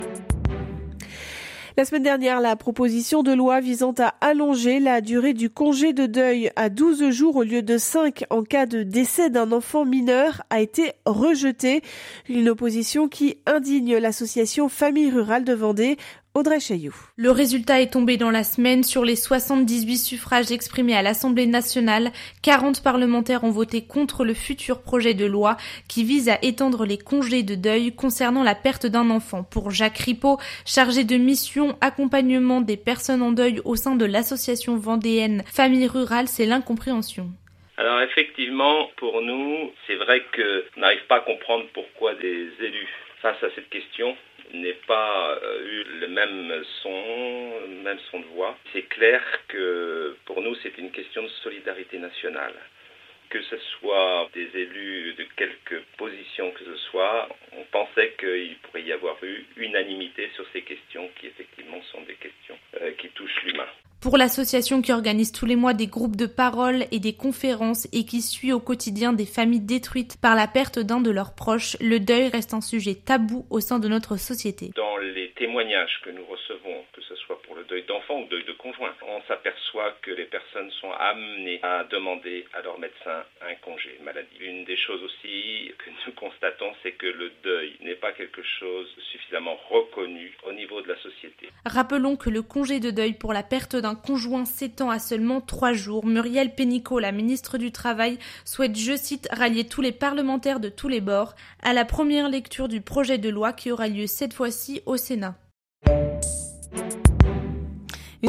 Interview également sur la radio RCF.
audio_interview_rcf_rejet_loi_deuil_enfant_2.mp3